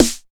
66 909 SNARE.wav